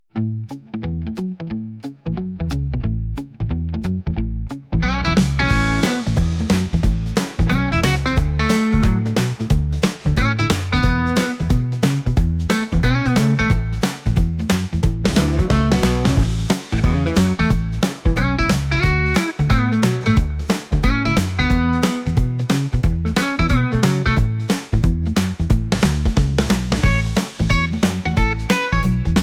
ギターとドラムで幕間を演出するような曲です。